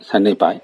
Cdo-fzho_38_(săng-sĕk-báik).ogg